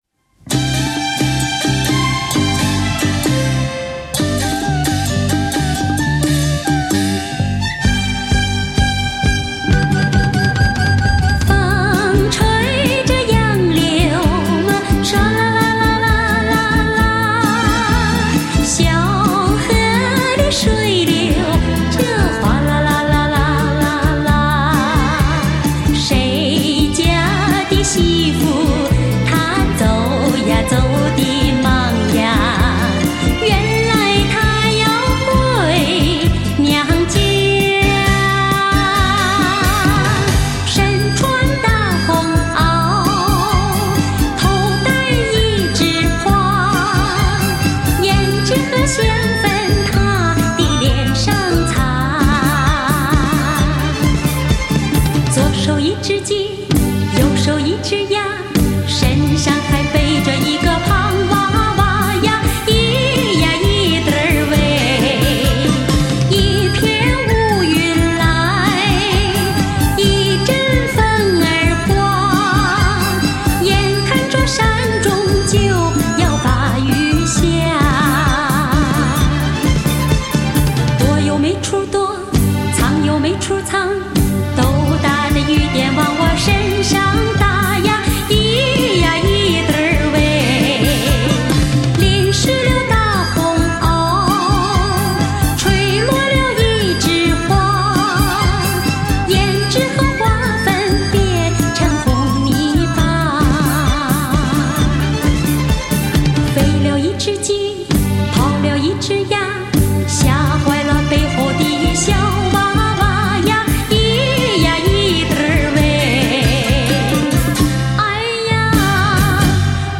温润甜美的音色，极高的声音密度，极强的空气感，质化数码音源。